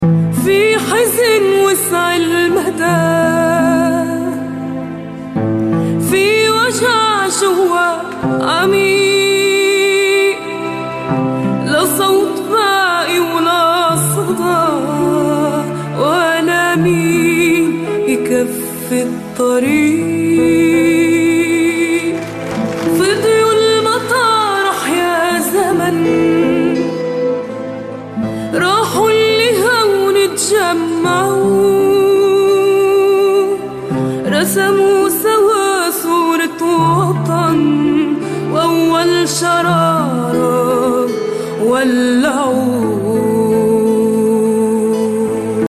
The occupation army documents scenes of bombing operations carried out in the city of Rafah